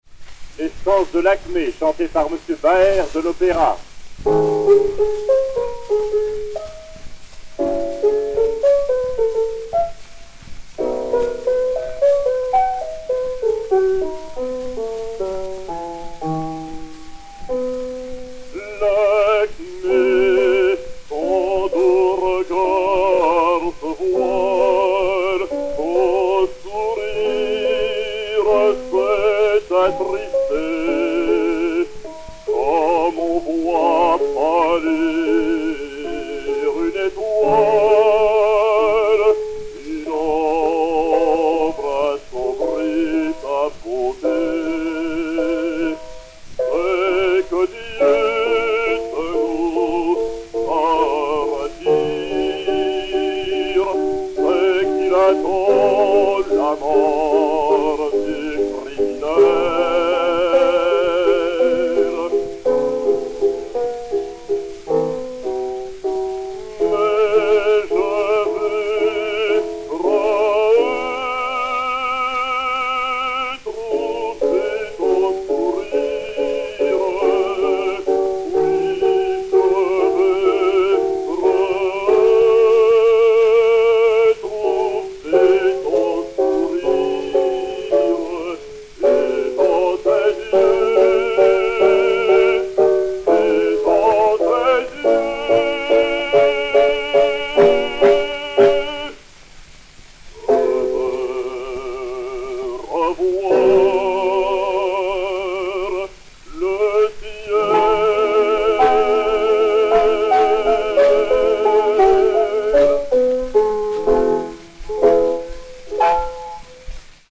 basse française
et Piano
Zonophone 12228, enr. à Paris vers 1903